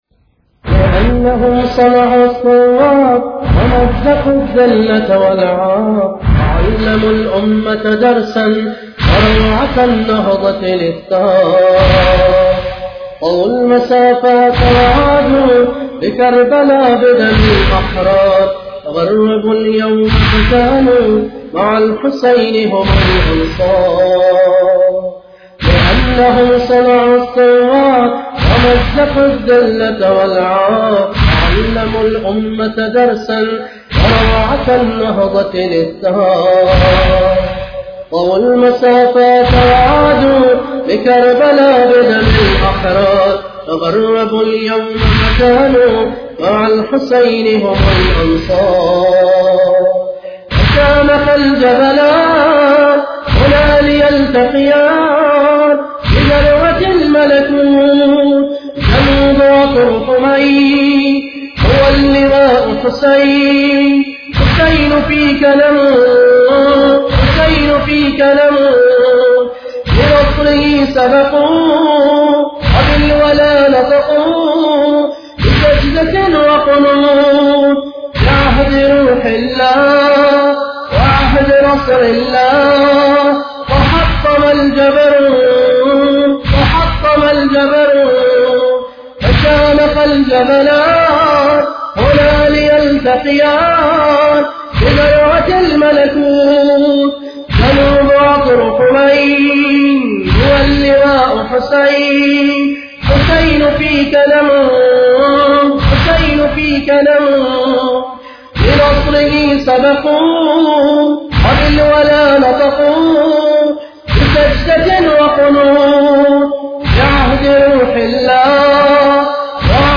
بصوت الرادود